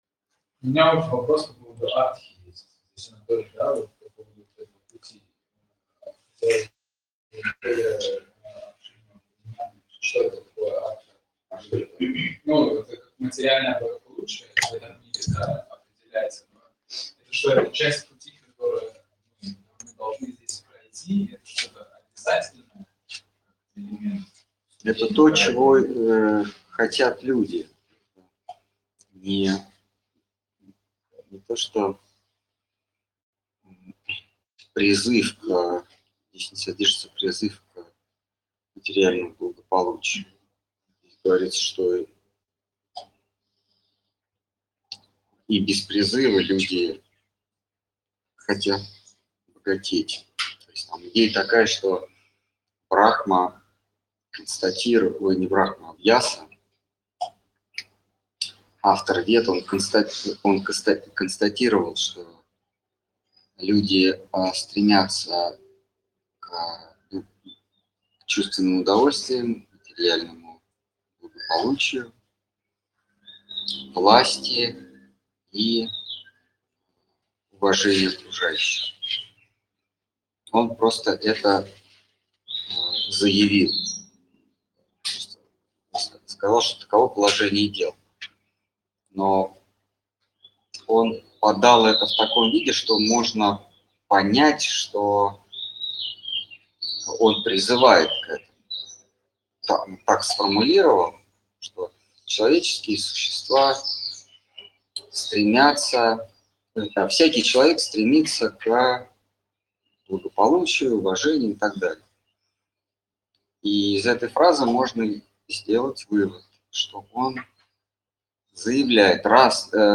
Ответы на вопросы из трансляции в телеграм канале «Колесница Джаганнатха». Тема трансляции: Слово Хранителя Преданности.